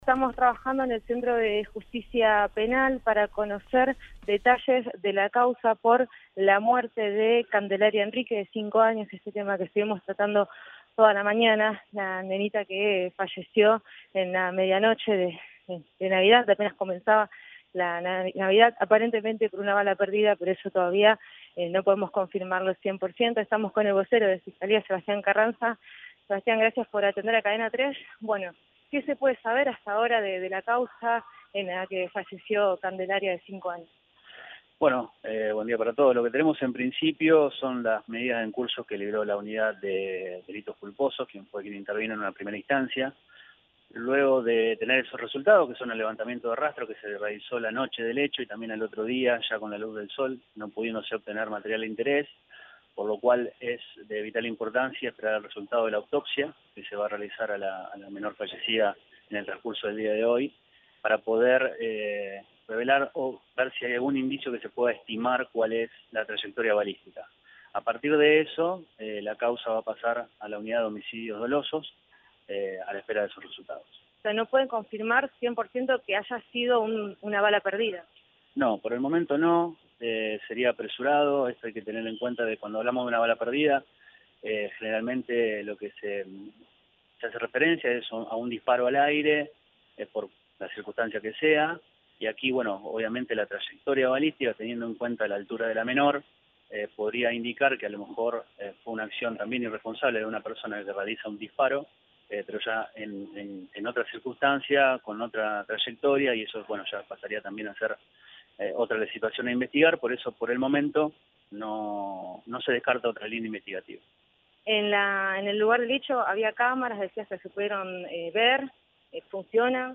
anticipó al móvil de Cadena 3 Rosario, en Siempre Juntos